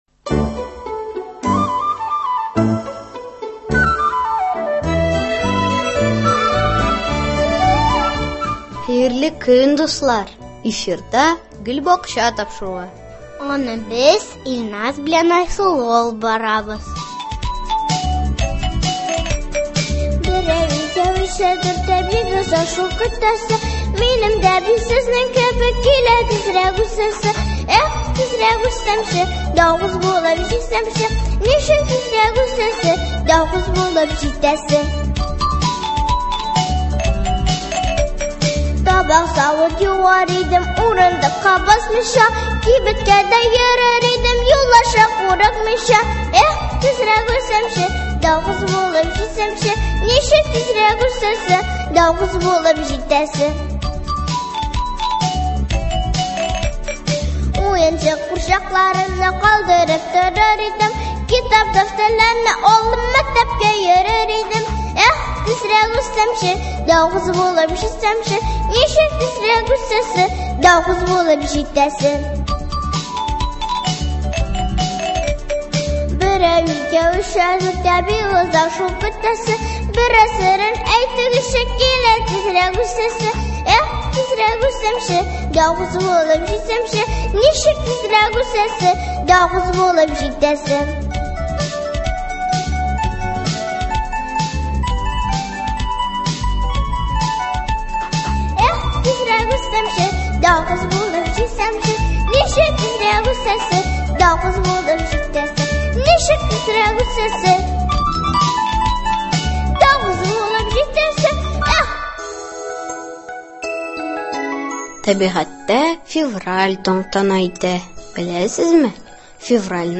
Бакчага йөрүче нәниләр чыгышларын тыңларбыз һәм герой шагыйрь Муса Җәлил иҗатын барлап аны искә алырбыз.